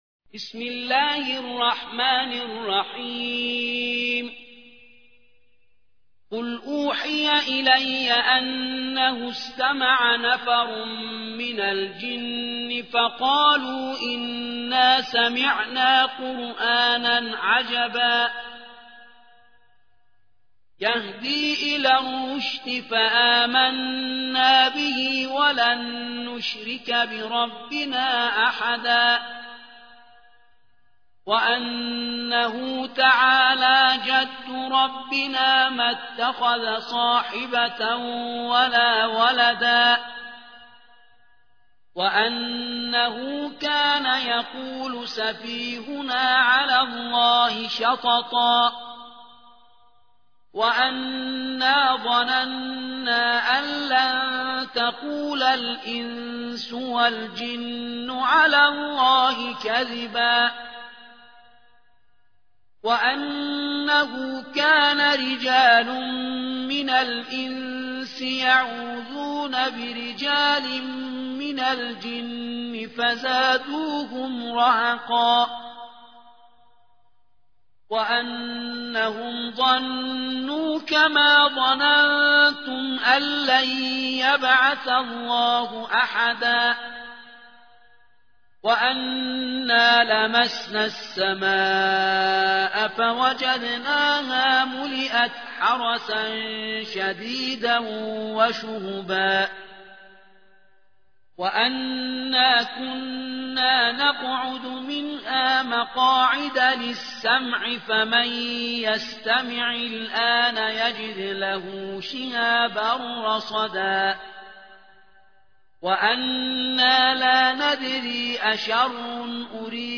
72. سورة الجن / القارئ